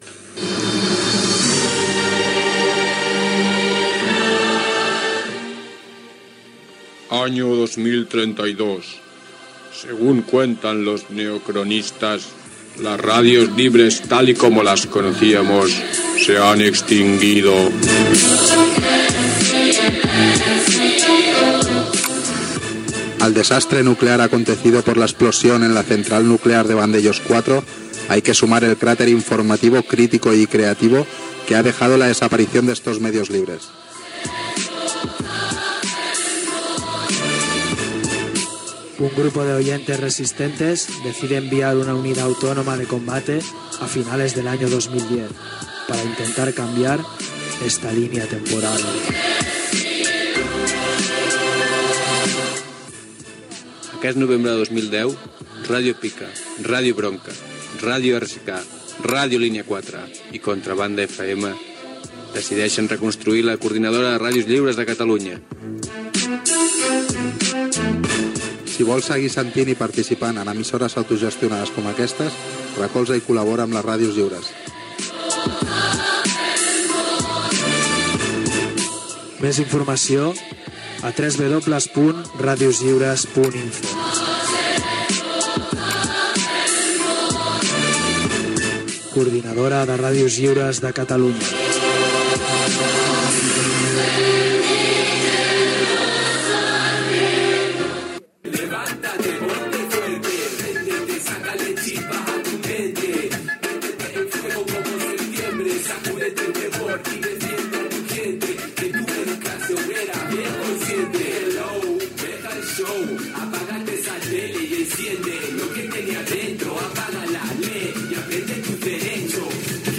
9560ced50acbc2200ee6f52ef365bd860e45b719.mp3 Títol Coordinadora Ràdios Lliures Emissora Coordinadora de Ràdios Lliures Titularitat Tercer sector Tercer sector Lliure Descripció Espai fet per la Coordinadora de Ràdios Lliures de Catalunya des de l'edifici 15 0 del carrer Almagro del barri de Canyelles de Nou Barris (Barcelona), que estava ocupat.
Info-entreteniment